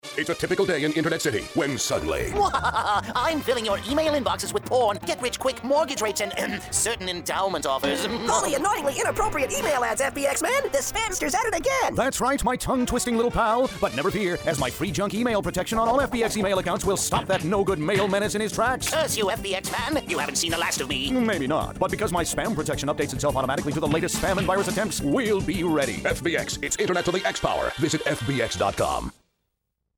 Holy hokey radio ads!
It's the second FBXMan radio spot, written by yours truly!